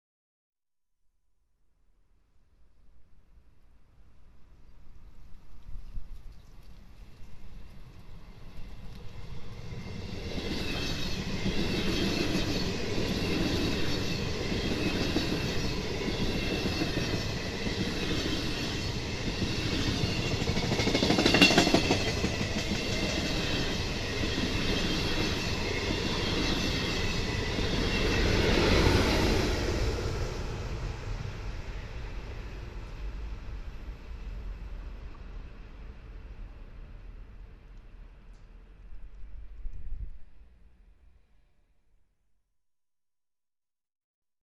Train 38842